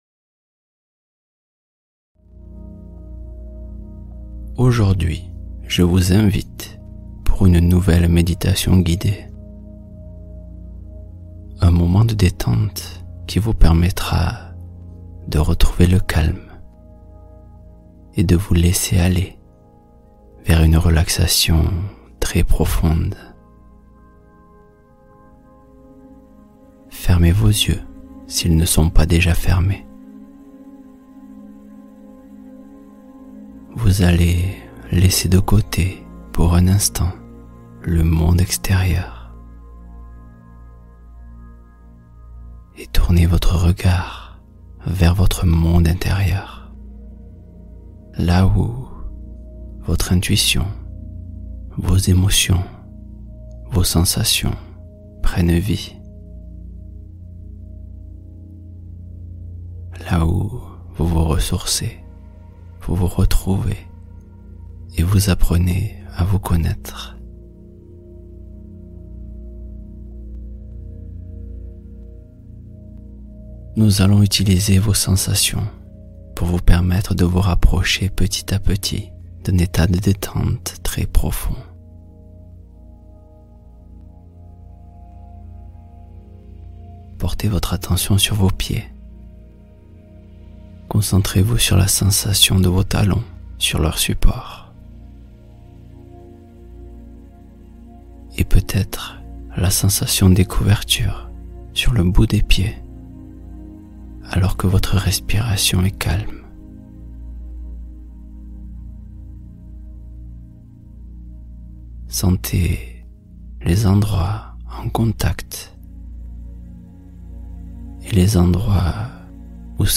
Calme Absolu : Méditation pour dissoudre les résistances intérieures